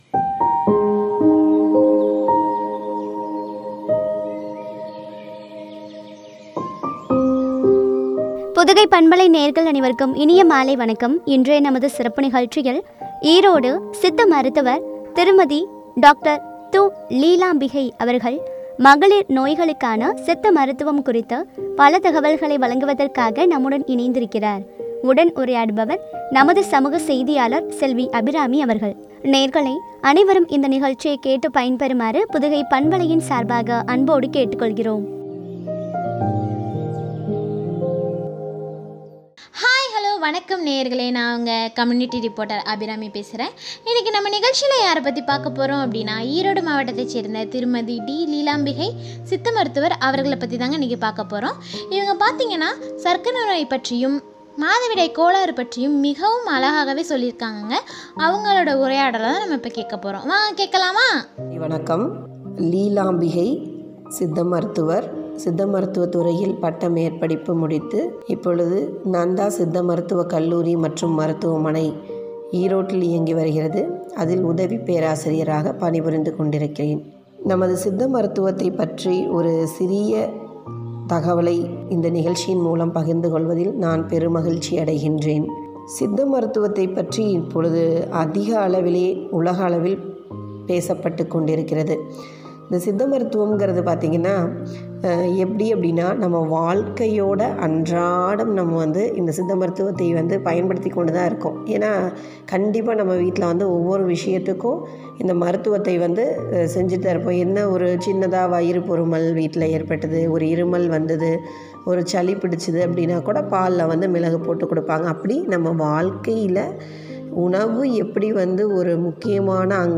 உரையாடல்.